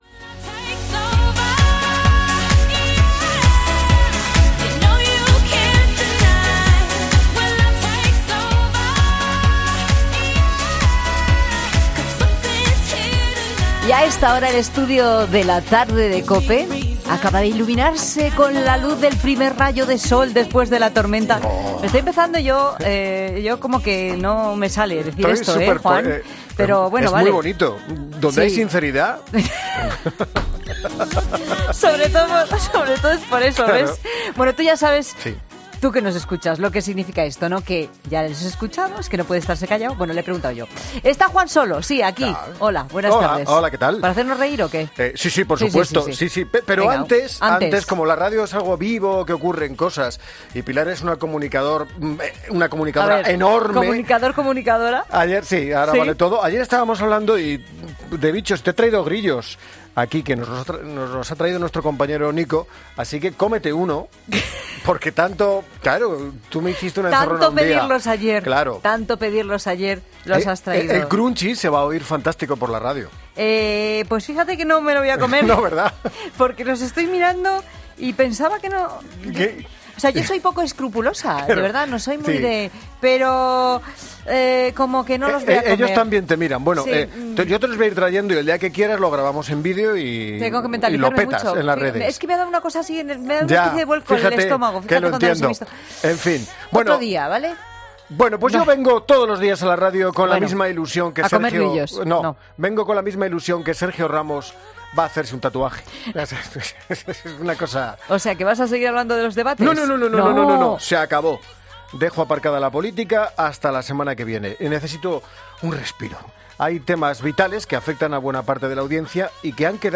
'La Tarde', un programa presentado por Pilar Cisneros y Fernando de Haro, es un magazine de tarde que se emite en COPE, de lunes a viernes, de 15 a 19 horas.